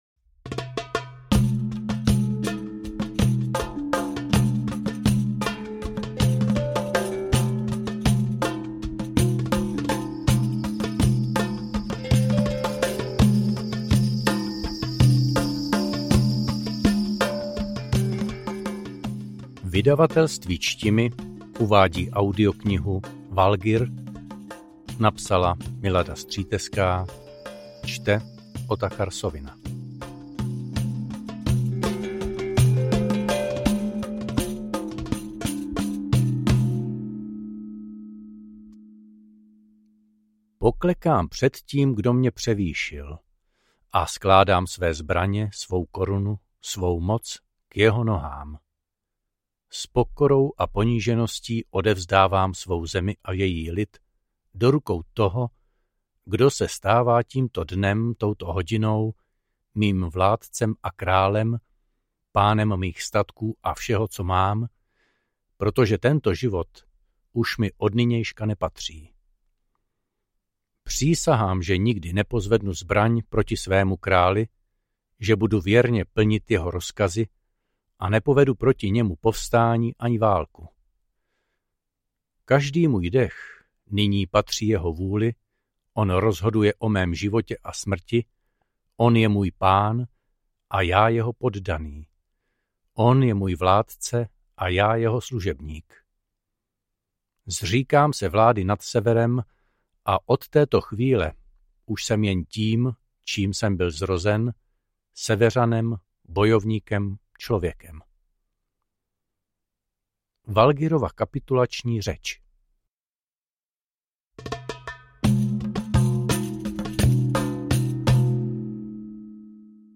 Skvělé zpracovaná audiokniha mé oblíbené autorky, doufám, že budete pokračovat dalšími díly